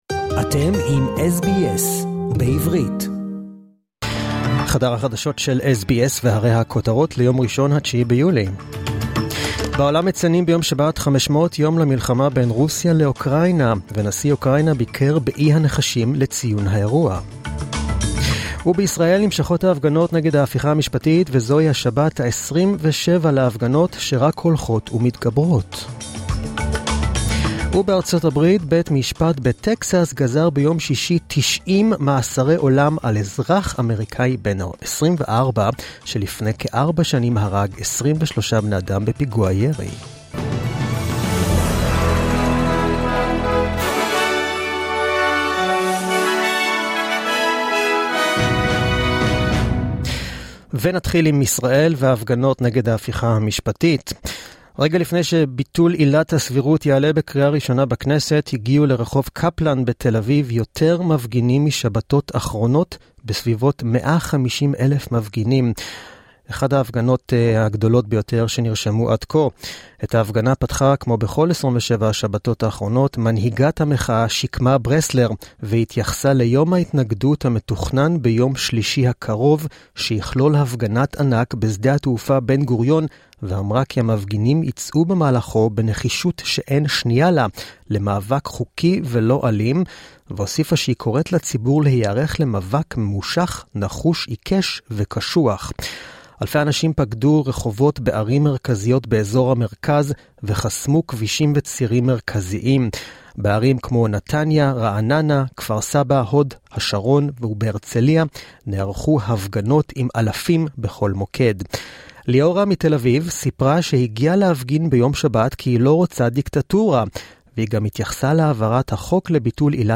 the latest news in Hebrew, as heard on the Hebrew program on SBS radio